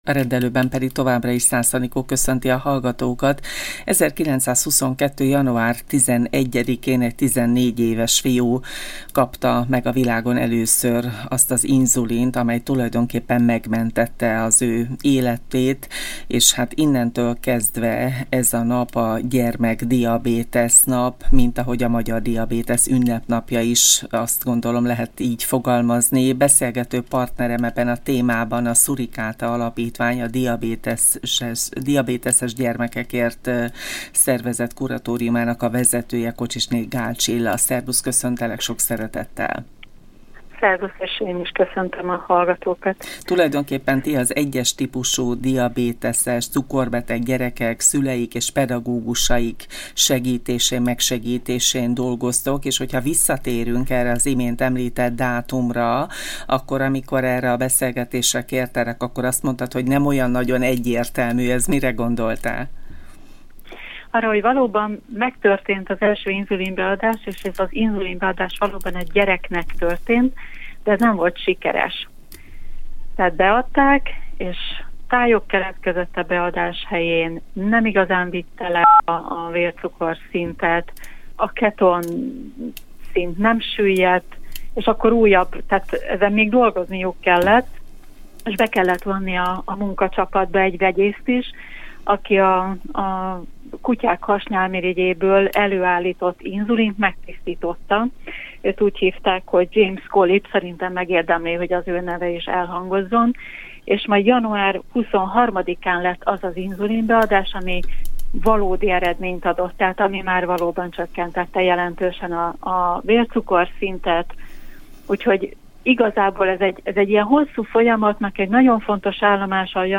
A beszélgetésben kiemelt hangsúlyt kapott, hogy melyek az I-es típusú diabétesz tünetei.